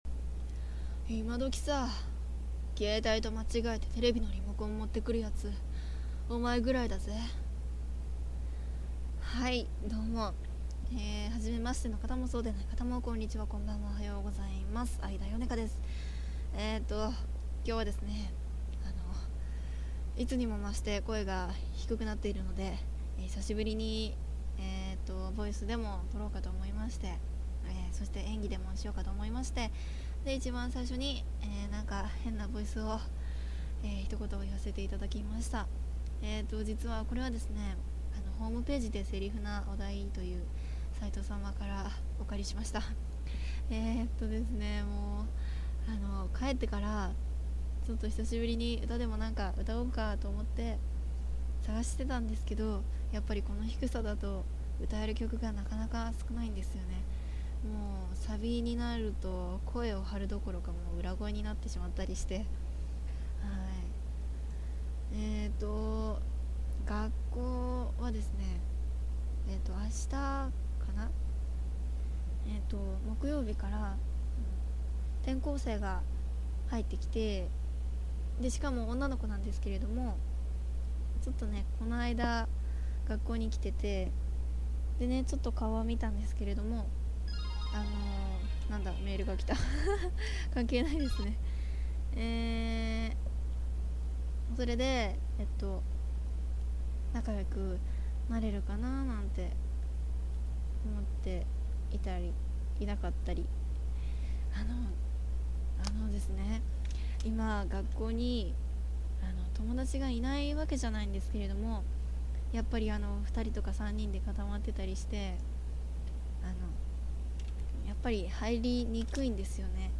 またボイスを録ってみたのでこそーりＵＰ。
元気なくてぼそぼそ…（・Ａ・｀）
なので聞き取りづらいかと思われますヘッドホン推奨．
↑携帯カチャカチャゆうてますorz